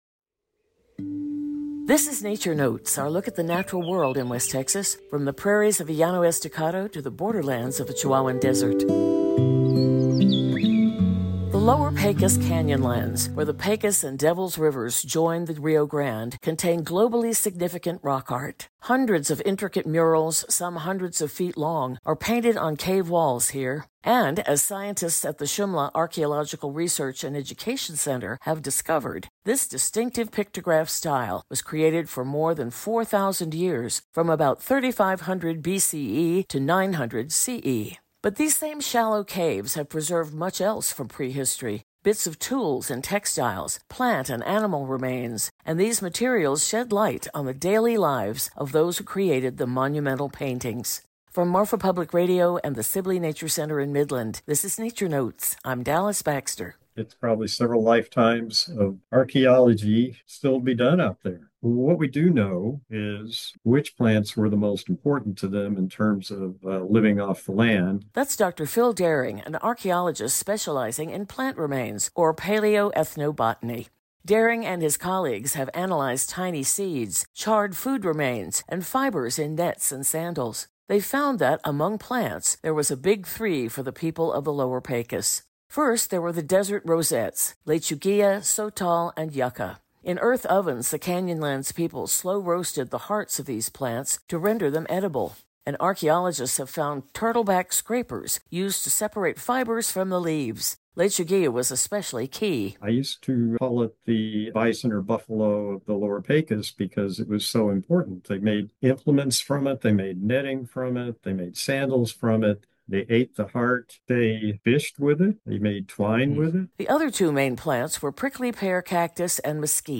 Through interviews with scientists and field recordings, this Marfa Public Radio original series reveals the secrets of desert life.